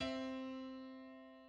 Decyma Wielka
harmonicznie   Decyma o rozmiarze szesnastu półtonów.
Jest konsonansem niedoskonałym.